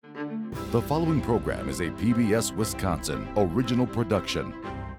PBS WI- ORIGINAL PROD HEADER 05_Male_REV 21.wav